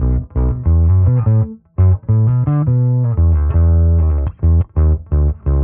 Index of /musicradar/dusty-funk-samples/Bass/85bpm
DF_PegBass_85-B.wav